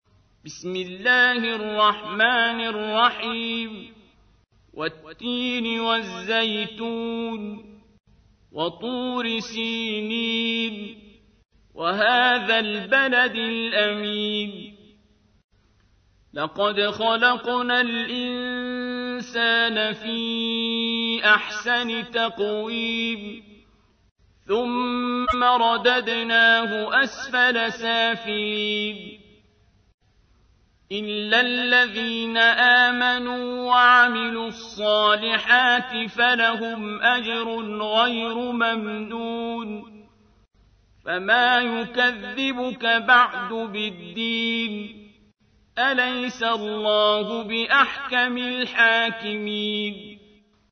تحميل : 95. سورة التين / القارئ عبد الباسط عبد الصمد / القرآن الكريم / موقع يا حسين